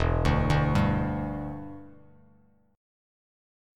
Em7 Chord
Listen to Em7 strummed